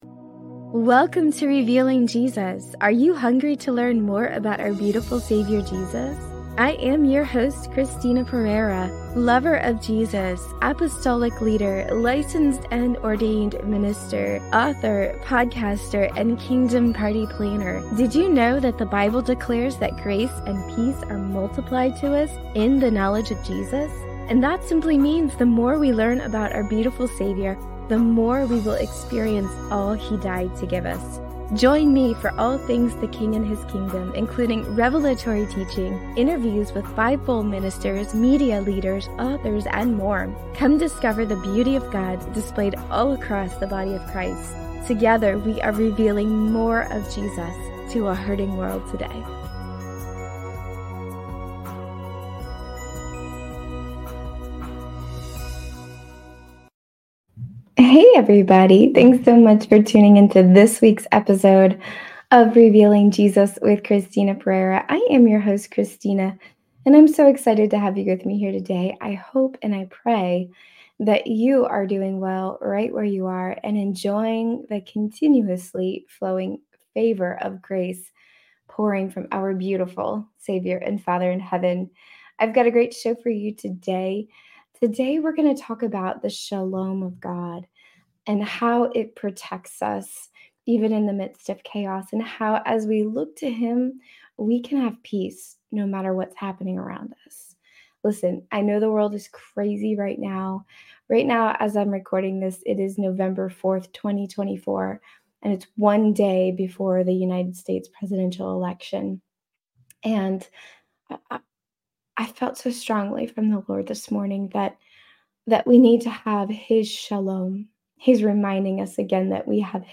Listen for revelatory teaching, interviews with Christian leaders, and testimonies of His goodness in our lives!